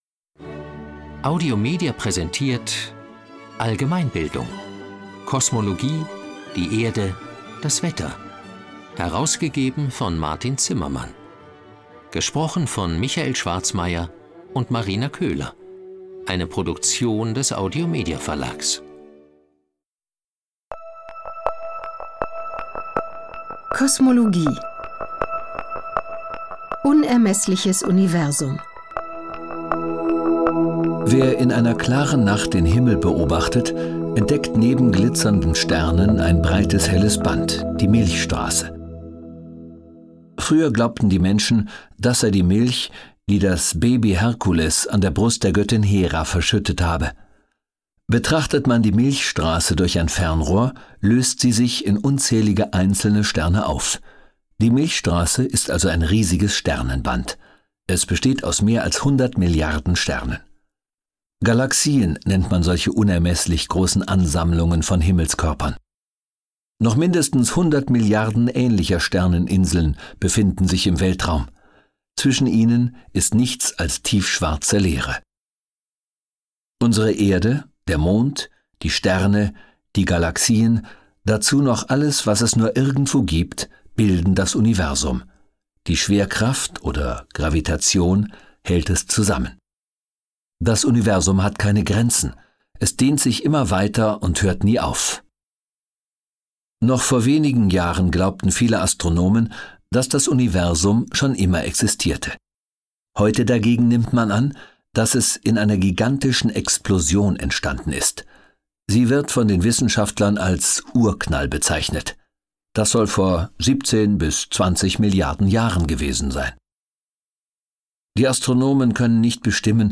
Die Erforschung des Weltalls gehört noch immer zu den größten Herausforderungen der Wissenschaft. Das Hörbuch gibt einen Überblick über unser Sonnensystem, Entstehungsgeschichte und Beschaffenheit der Erde sowie Wetterphänomene und klimatische Entwicklungen.